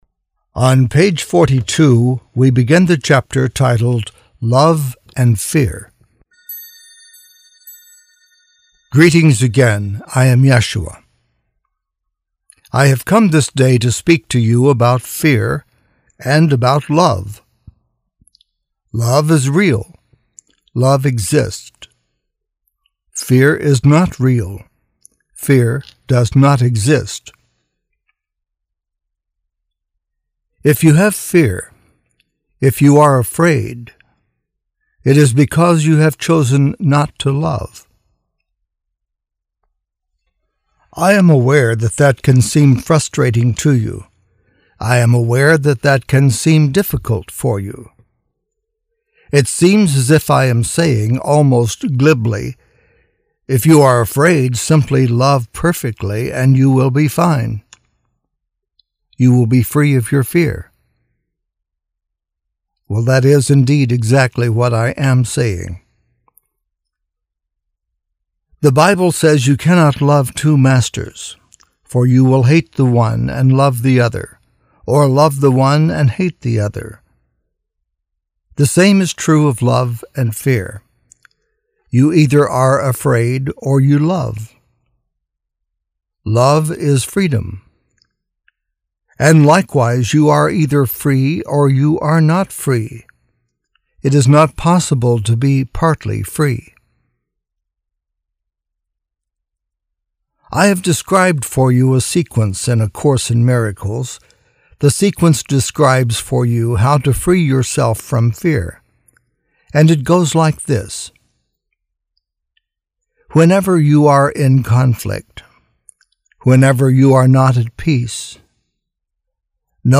JESHUA Audiobooks